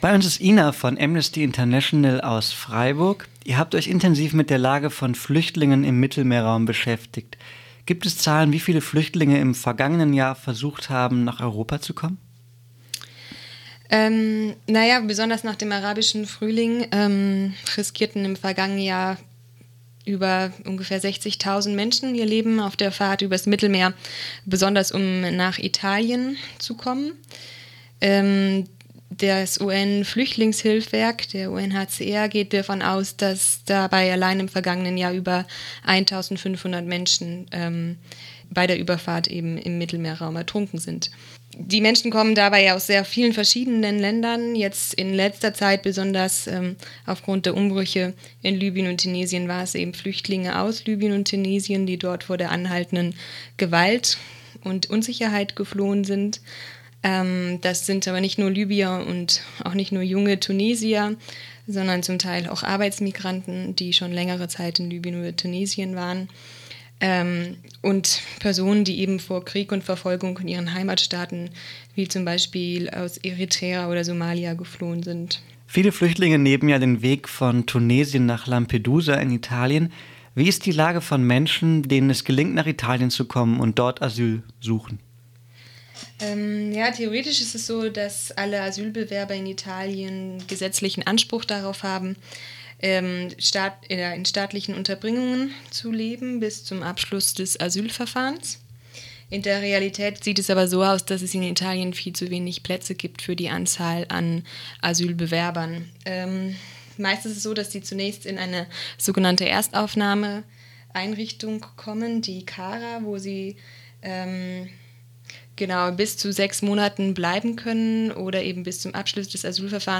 Flüchtlingspolitik und Menschenrechte in der EU- Interview